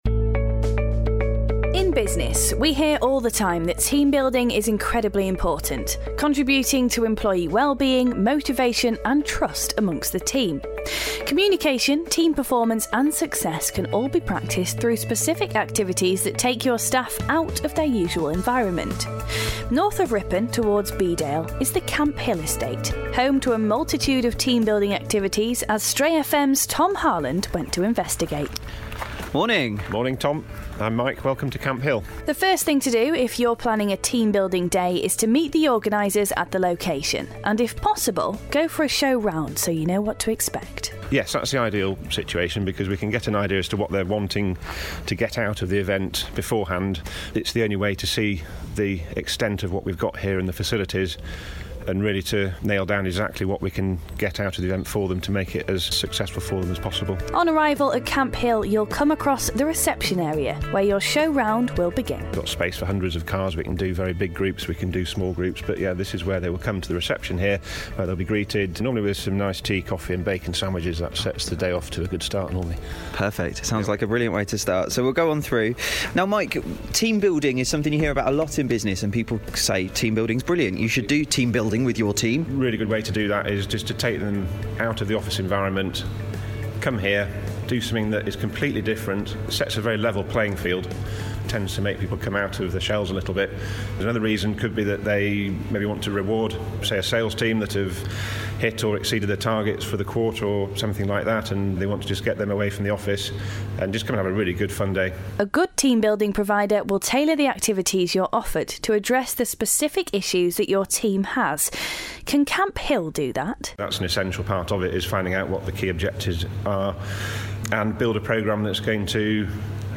We head to the Camp Hill Estate to investigate the phenomenon that is team building